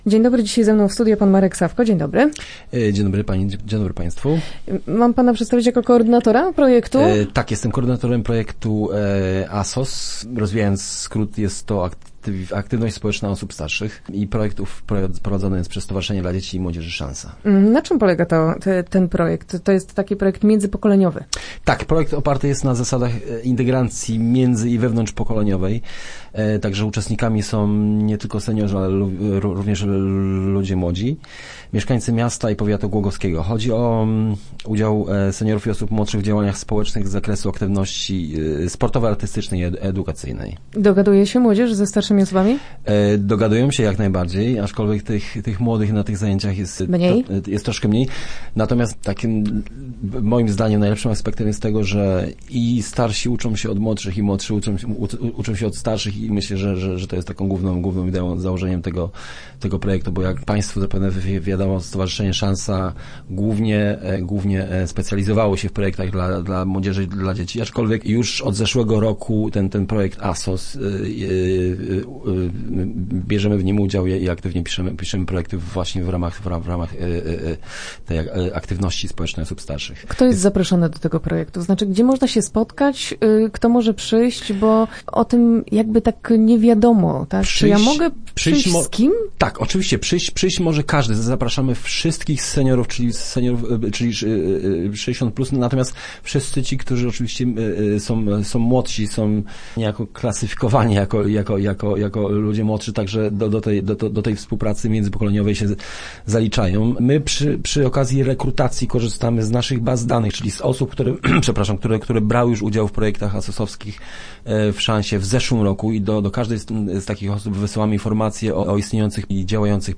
Start arrow Rozmowy Elki arrow Integrują pokolenia